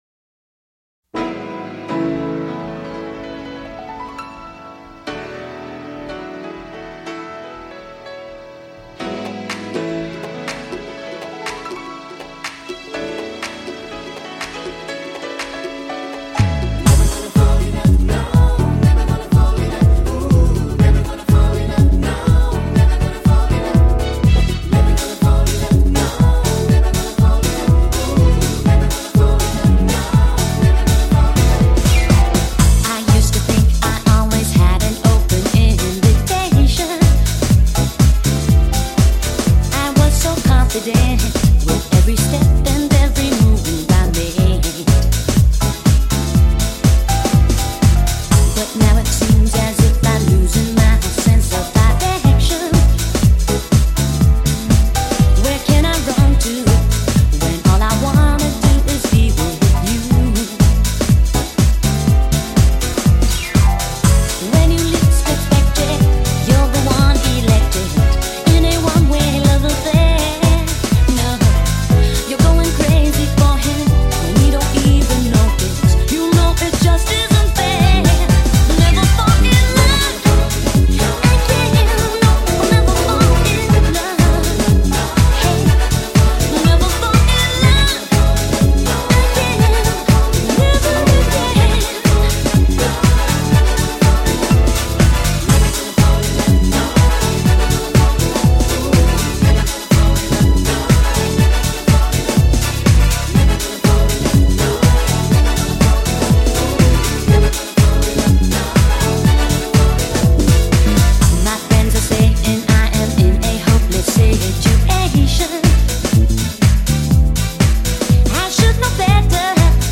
ジャンル(スタイル) JAPANESE POP / FREE SOUL / HOUSE